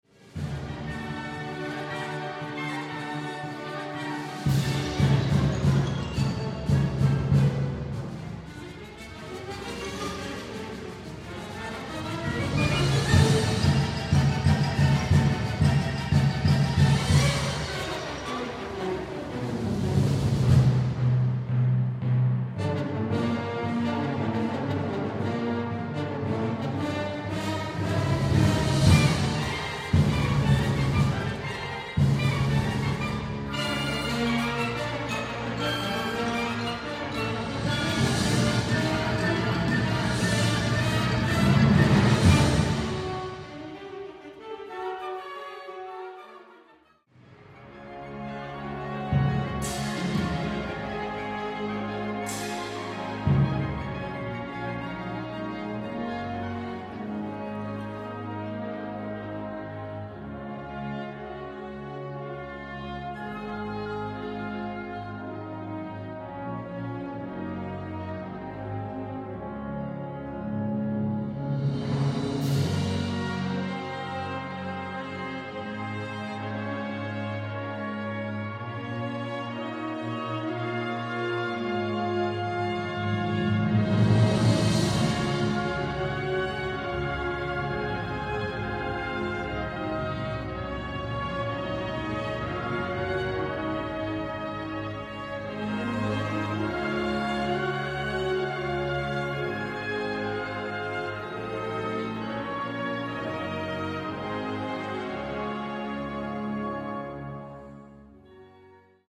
＜吹奏楽譜オリジナル作品＞